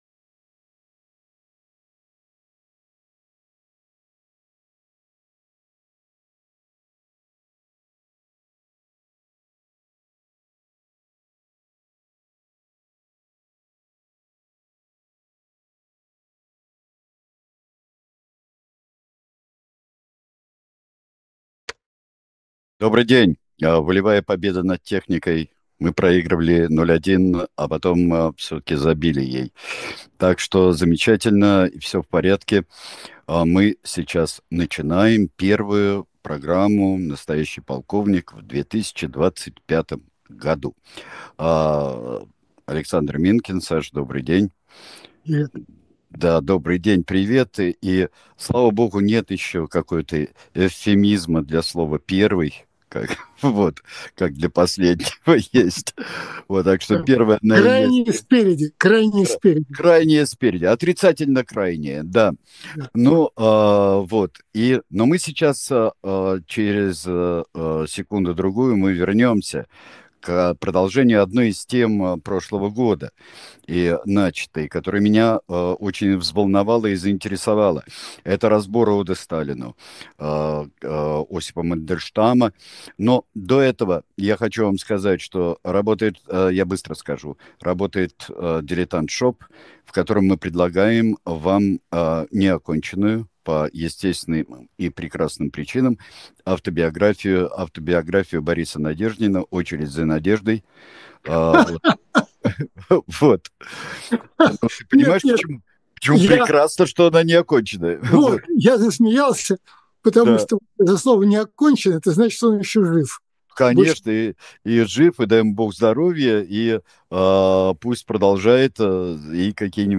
Эфир ведут Александр Минкин и Сергей Бунтман